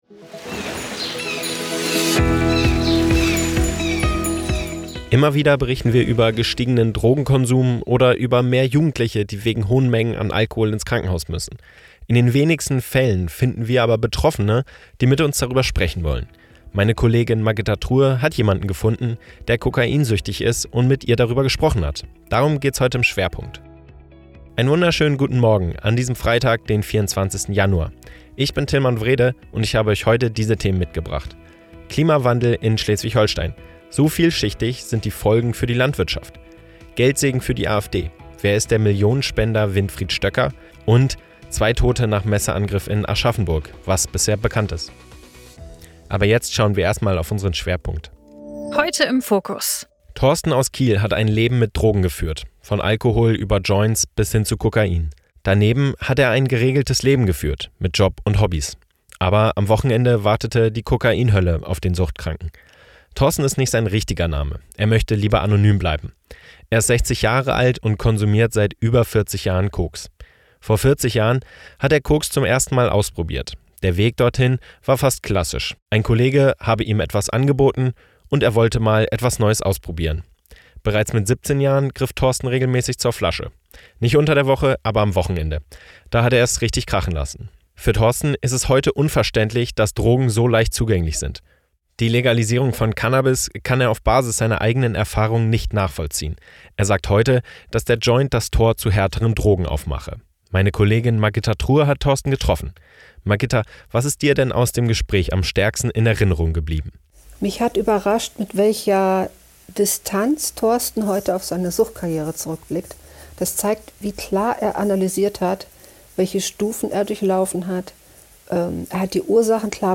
24.01. Kokainsüchtiger berichtet: “Alkohol und Cannabis waren meine Einstiegsdrogen”
Dein News-Podcast für Schleswig-Holstein